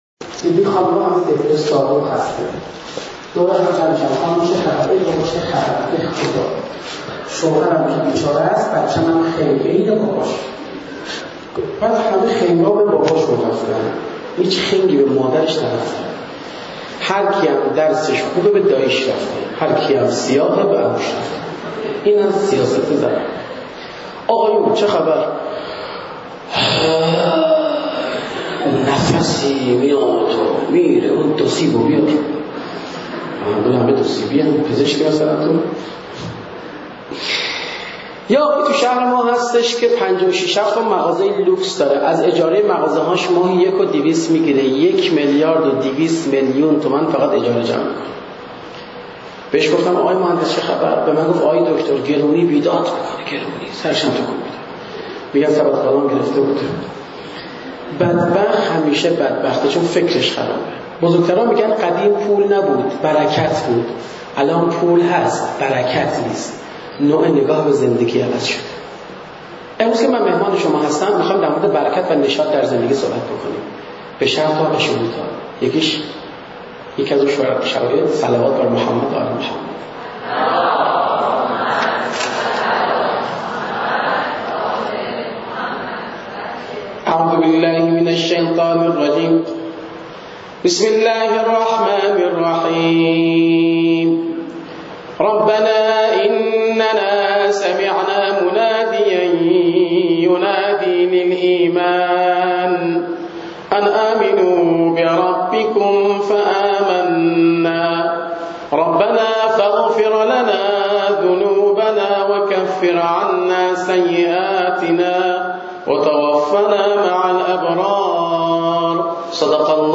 نوزدهمین کارگاه ازدواج دانشجویی دانشگاه علوم پزشکی تهران برگزار شد